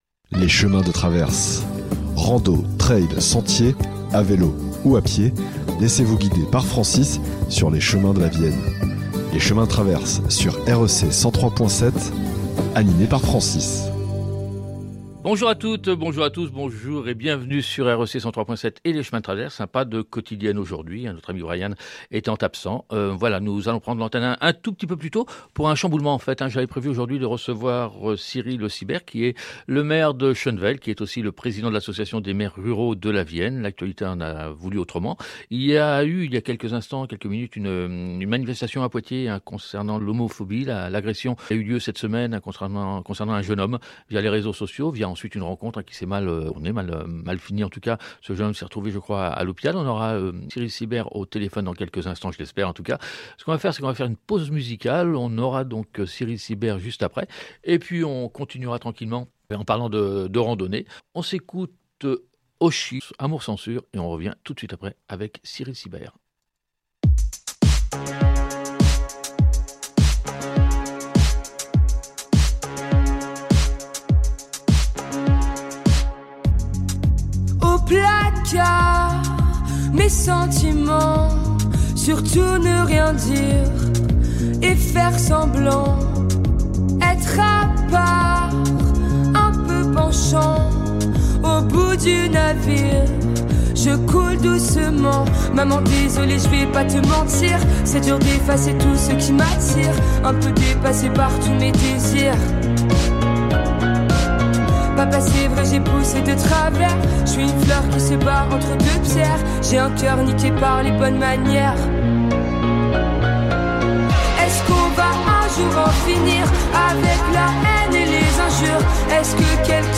Bien que la qualité du son ne reflète ni celle de l’échange et de l’engagement politique pour l’un ou associatif pour l’autre.